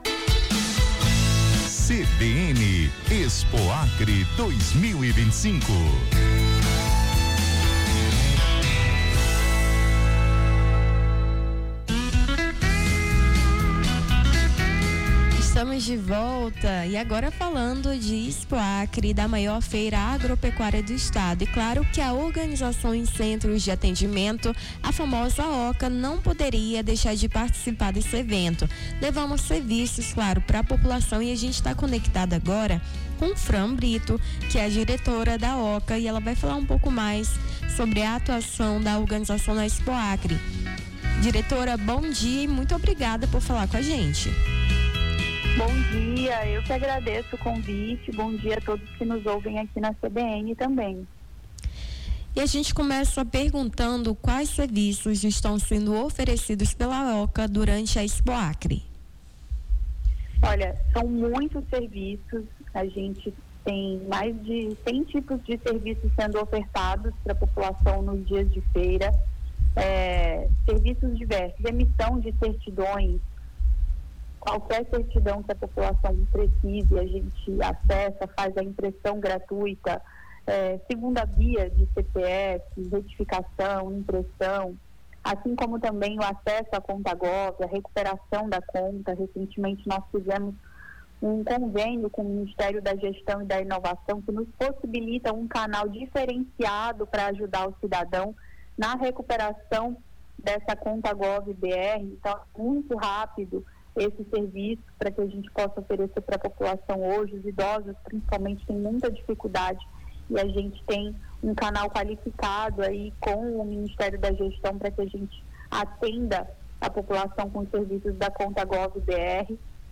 Nome do Artista - CENSURA - ENTREVISTA (SERVIÇOS OCA EXPOACRE) 30-07-25.mp3